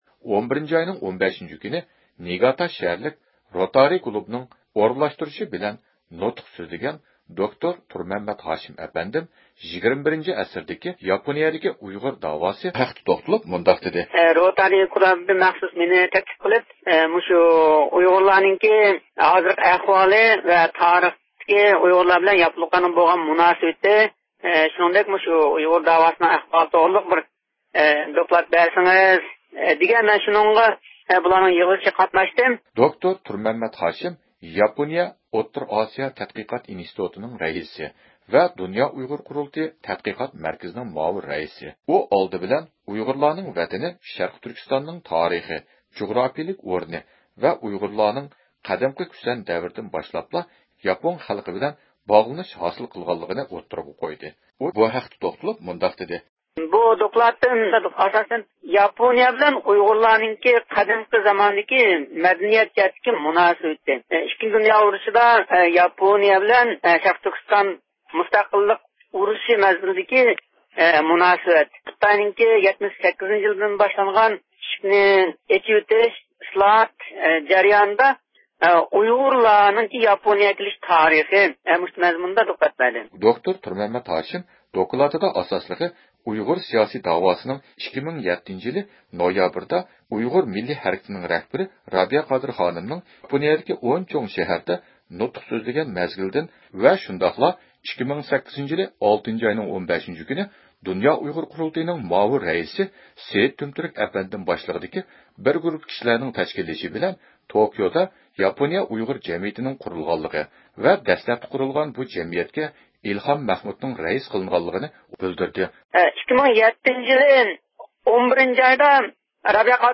ئىختىيارى مۇخبىرىمىز